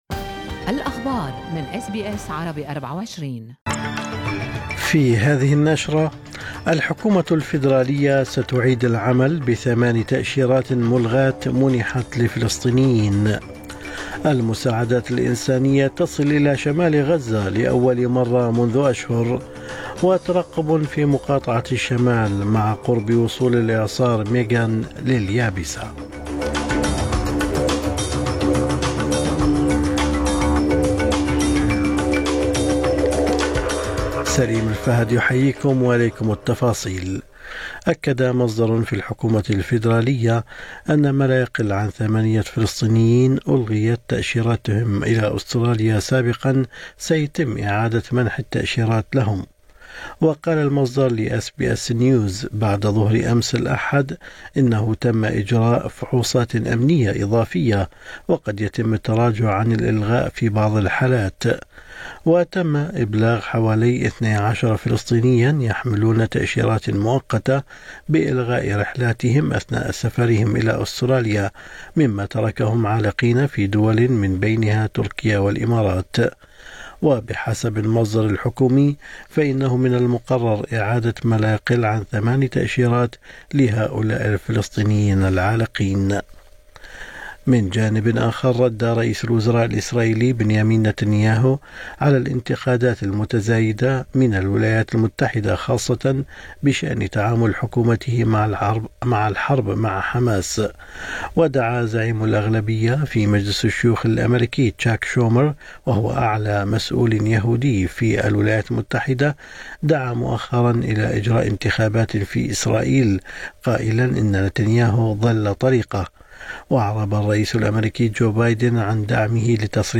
نشرة أخبار الصباح 18/3/2024